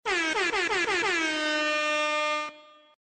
PLAY HornHornHorn
hornhorn-1.mp3